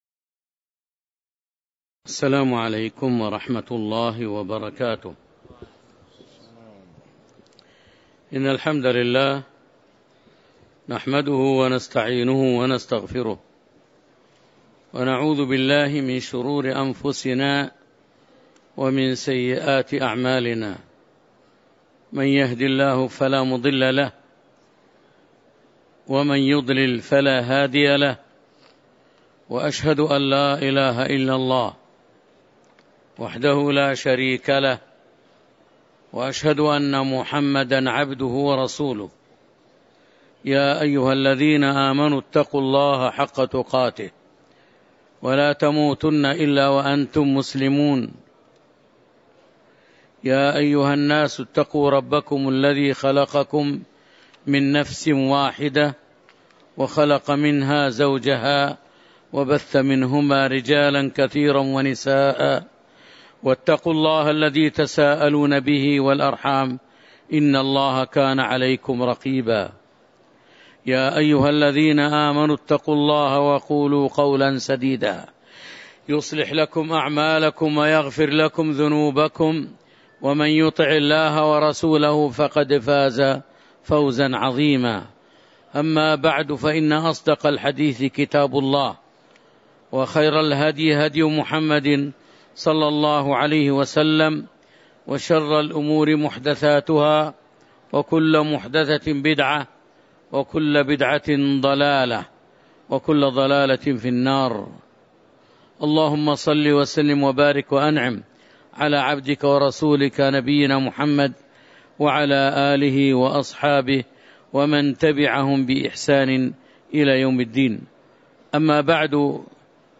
تاريخ النشر ٢٩ جمادى الأولى ١٤٤٦ هـ المكان: المسجد النبوي الشيخ